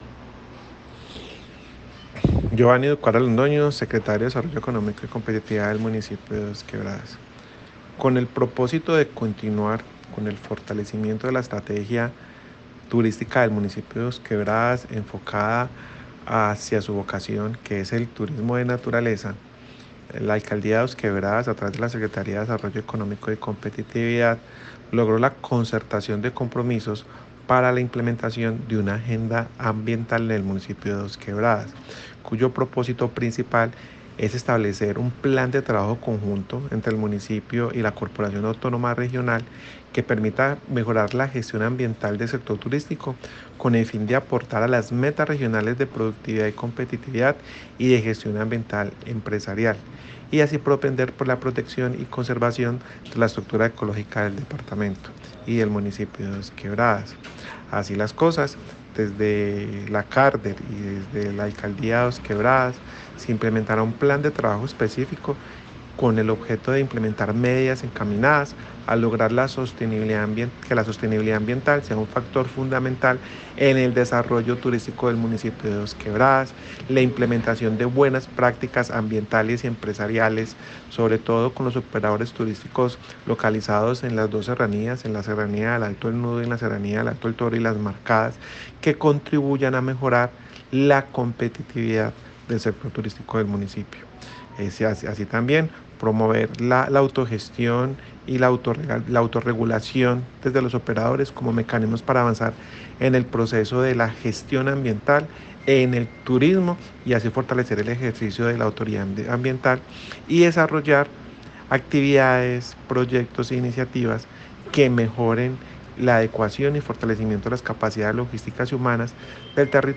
Escuchar Audio: Secretario de Desarrollo Económico y Competitividad, Geovanny Ducuara Londoño.
Comunicado-050-Audio-Secretario-de-Desarrollo-Economico-y-Competitividad-Geovanny-Ducuara-Londono.mp3